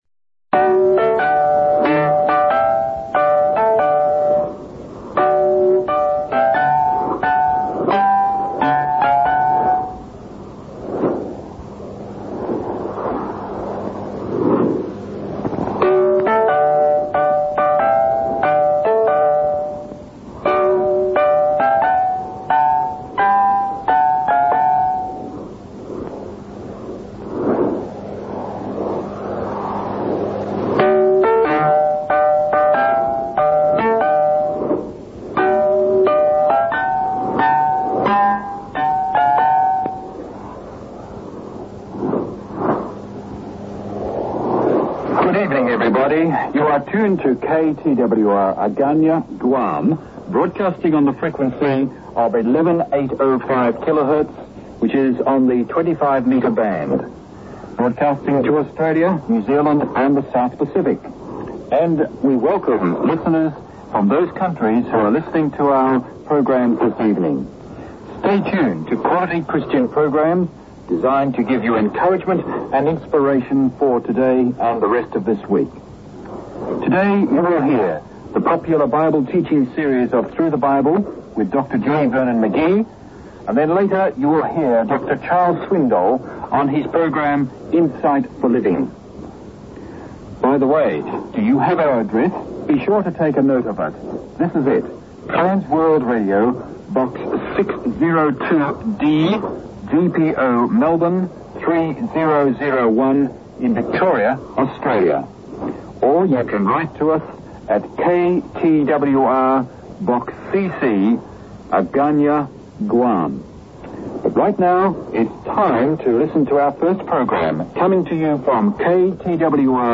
Sendezeichenarchiv Ozeanien / Pazifik
A = Auslandsdienst / I = Inlandsdienst / R = Regional/Lokal / P = Pirat/Untergrund / H = Historisch // S = Studioaufnahme / M = Mitschnitt / U = Unzulänglich
Link; A: KTWR Agana (S/M) [alte IS, neue IS]